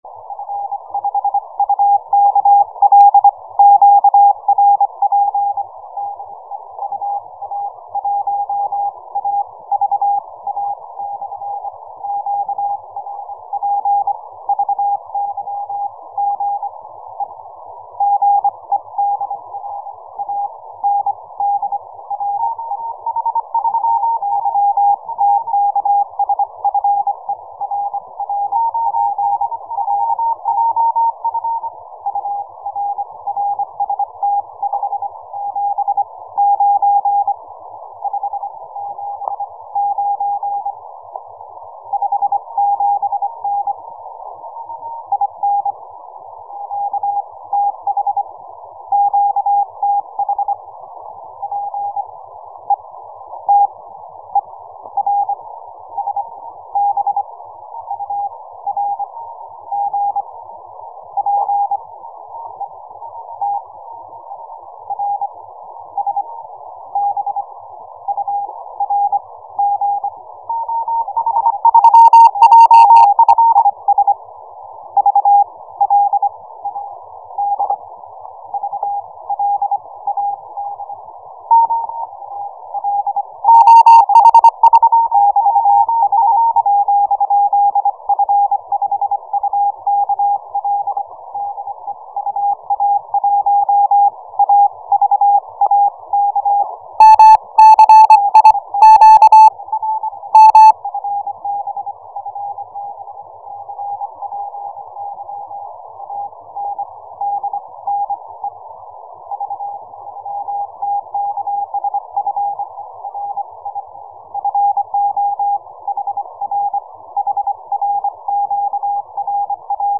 CW - QSO